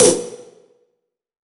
SNARE 103.wav